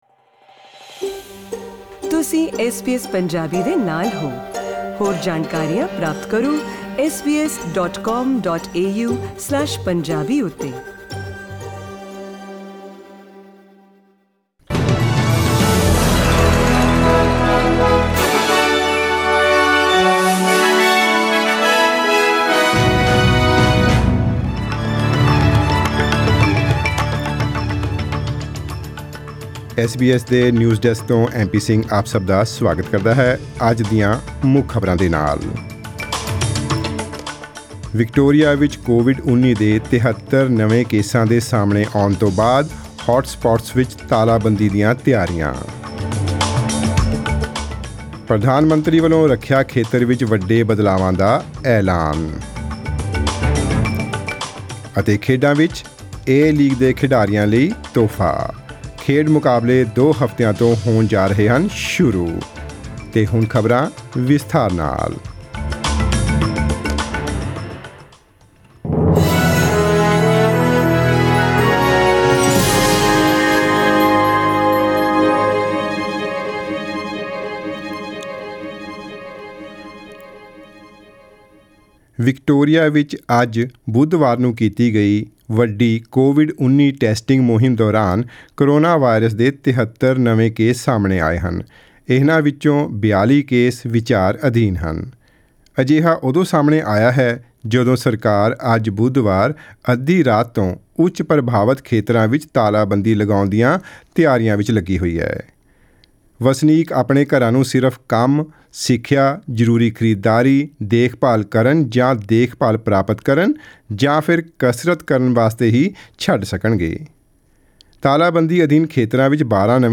In today’s news bulletin